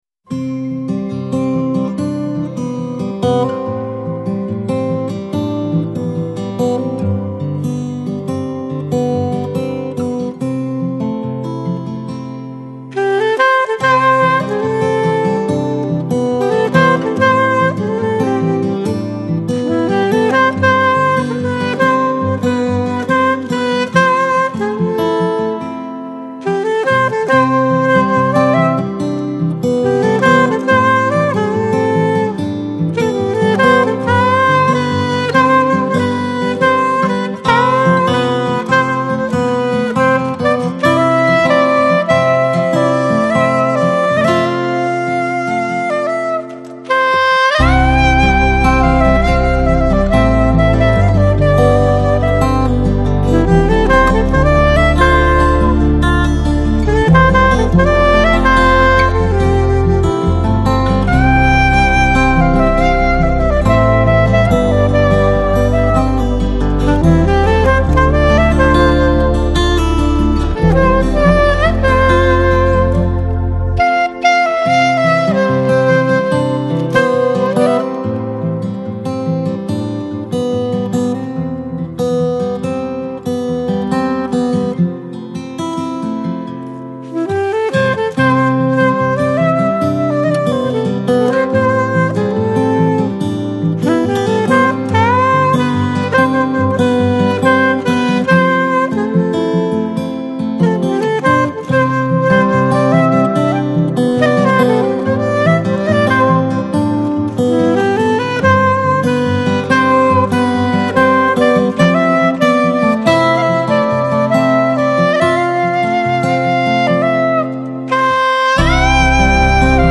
Жанр: Lounge, Chillout, Smooth Jazz, Easy Listening